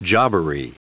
Prononciation du mot jobbery en anglais (fichier audio)
Prononciation du mot : jobbery